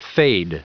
Prononciation du mot fade en anglais (fichier audio)
Prononciation du mot : fade